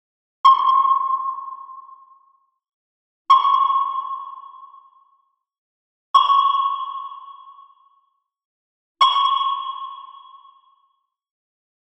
SOS Tutorial 269 Logic Pro X - U-Boot Sonar SFX Teil 01
In diesem zweiteiligen Sound-Design-Tutorial wollen wir das charakteristische Sonargeräusch von U-Booten erstellen.
Den damit erstellten Sound wollen wir dann in SOS Tutorial 270 mit einer Reihe von Audio-Effekten weiterbearbeiten (Klangbeispiel 02).